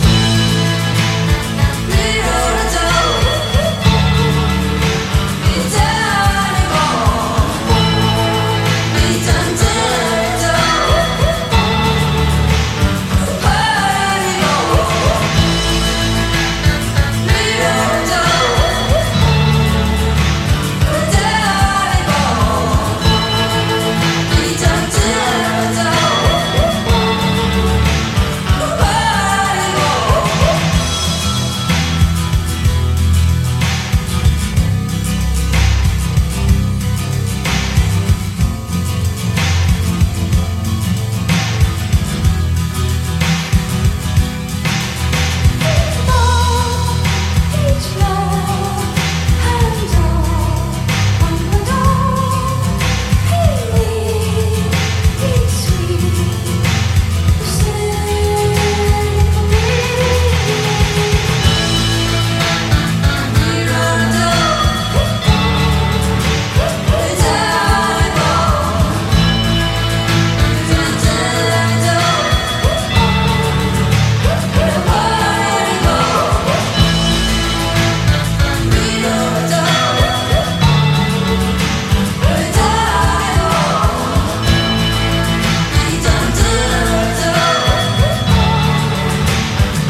ROCK / PUNK / 70'S/POWER POP/MOD / POST PUNK / NEW WAVE